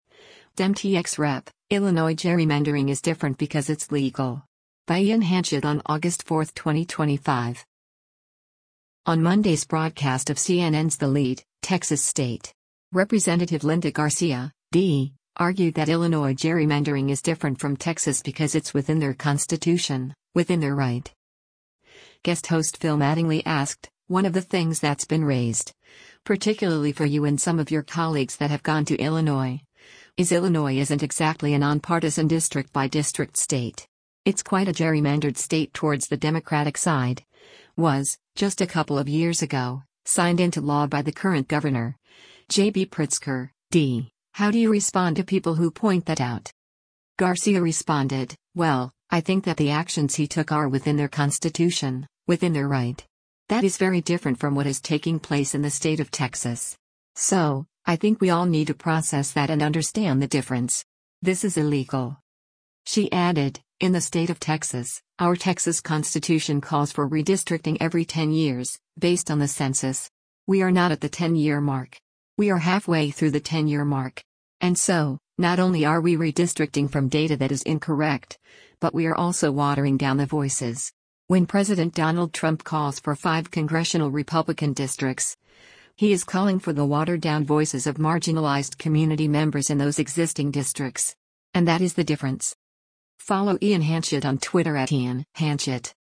On Monday’s broadcast of CNN’s “The Lead,” Texas State. Rep. Linda Garcia (D) argued that Illinois’ gerrymandering is different from Texas because it’s “within their constitution, within their right.”